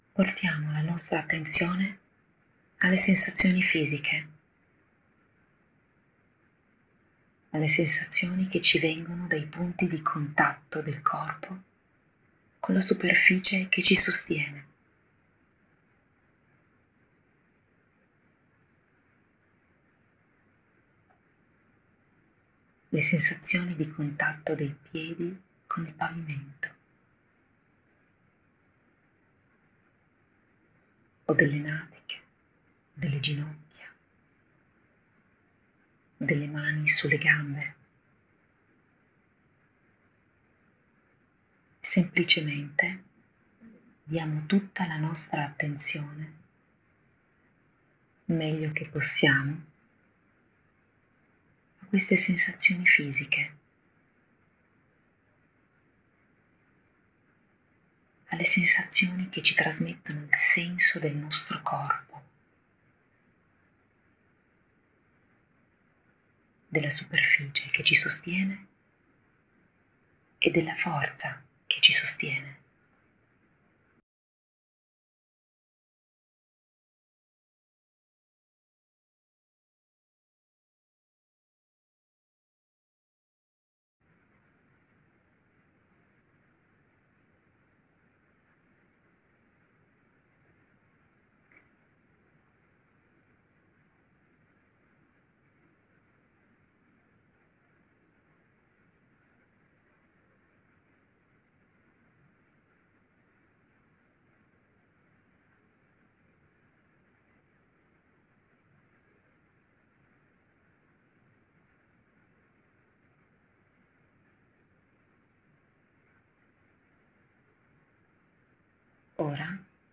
Meditazione guidata sul respiro
Dopo diverse volte (ognuno il suo tempo) sarà anche possibile procedere in autonomia senza ascoltare la voce che guida nella meditazione, semplicemente fidandosi di ciò che si è imparato.